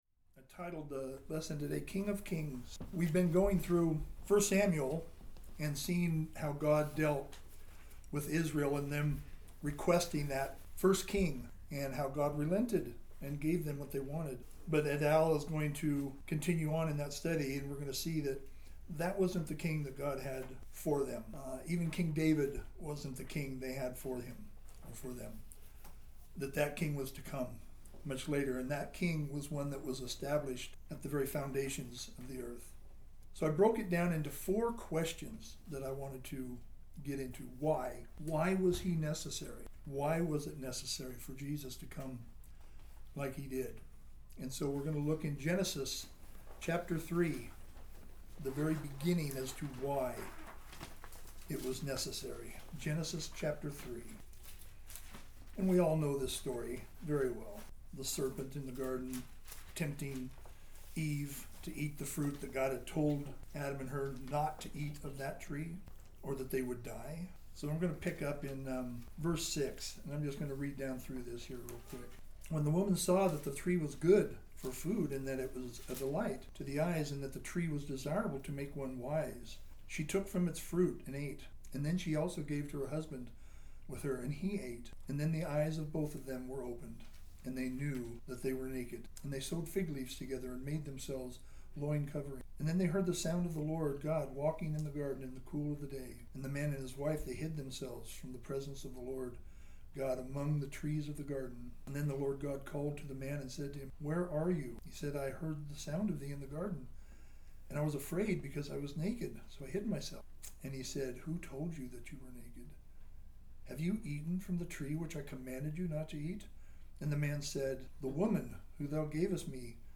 Service Type: Christmas Message